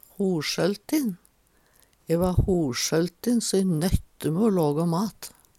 hoLsøltin - Numedalsmål (en-US)